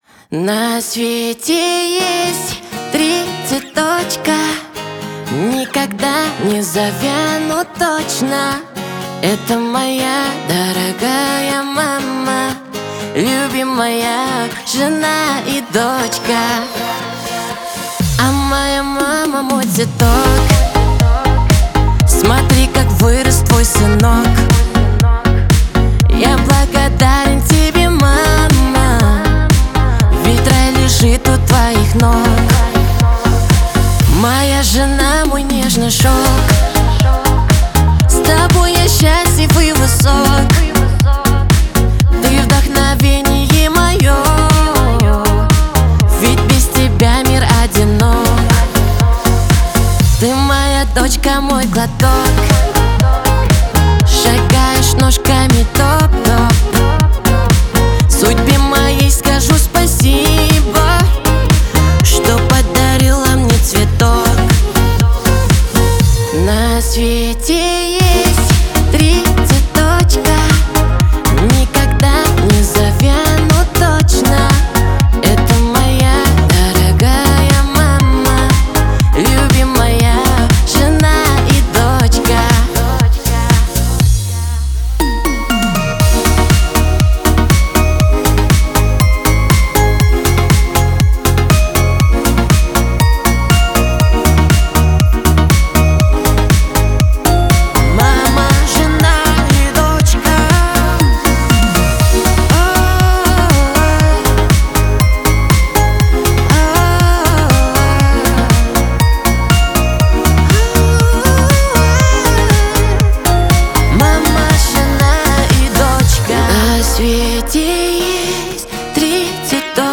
Жанр: Казахские